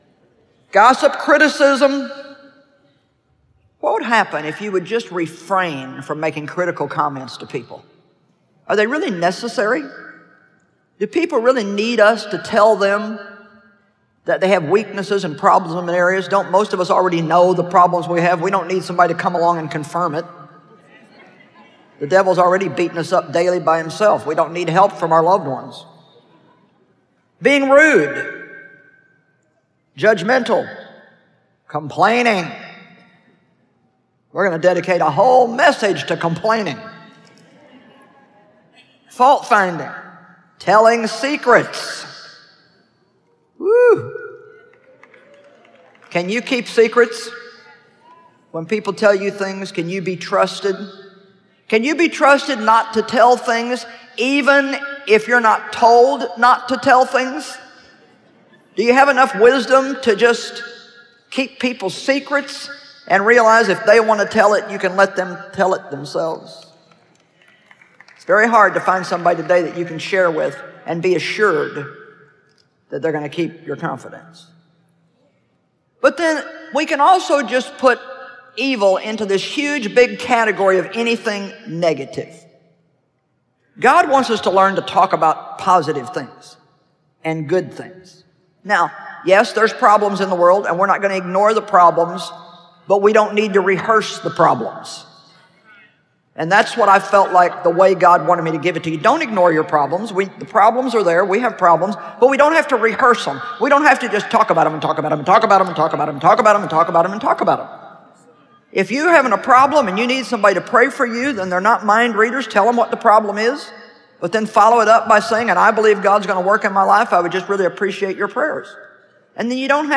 Change Your Words, Change Your Life Audiobook
Narrator